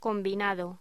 Locución: Combinado
voz